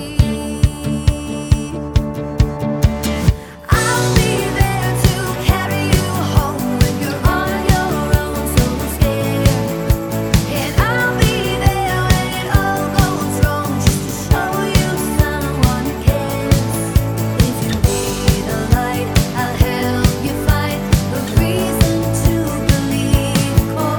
for solo singer Country (Female) 3:36 Buy £1.50